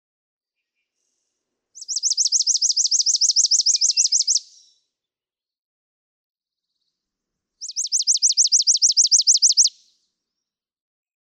Chipping sparrow
Look Park, Northampton, Massachusetts.
♫191—one song from each of two neighboring males
191_Chipping_Sparrow.mp3